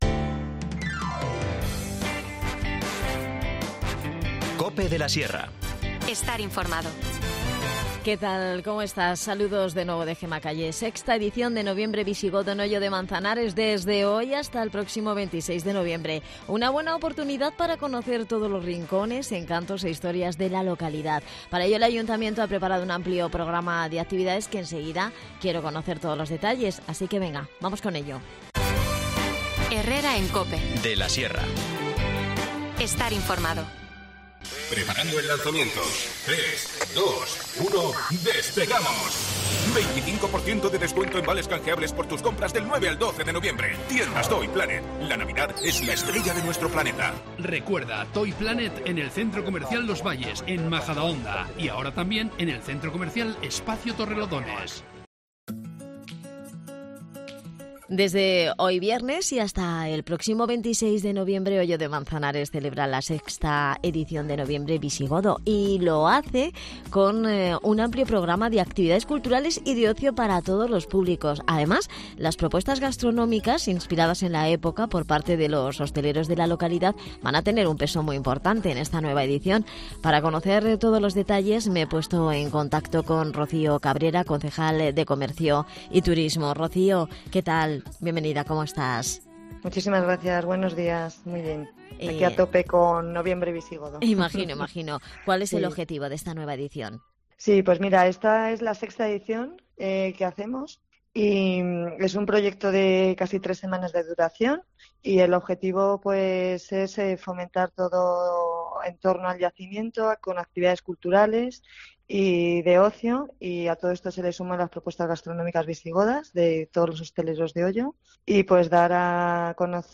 Nos adelanta todos los detalles y la programación Rocío Cabrera, concejal de Turismo.
Las desconexiones locales son espacios de 10 minutos de duración que se emiten en COPE, de lunes a viernes.